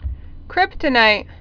(krĭptə-nīt)